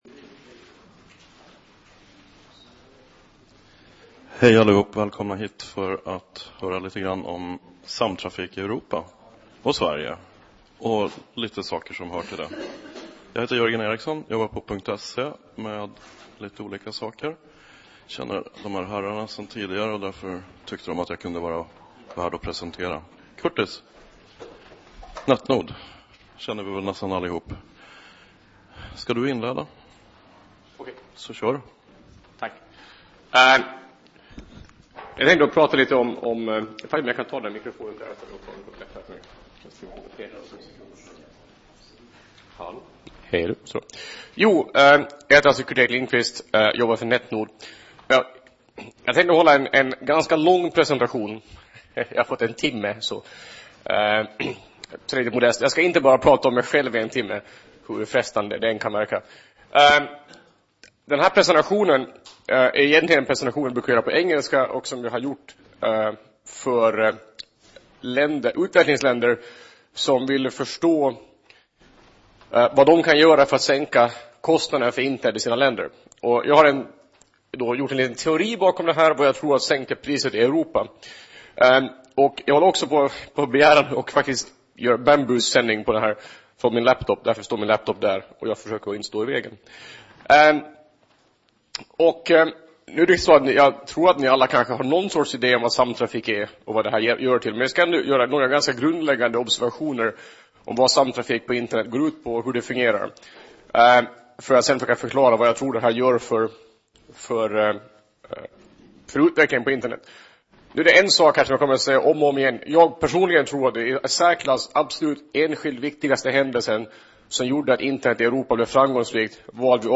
Konsolideringen bland Internetoperatörerna leder till färre och större operatörer och förändrar förutsättningarna för hur samtrafiken ska organiseras. Vi får höra företrädare för några knutpunkter berätta om utvecklingen.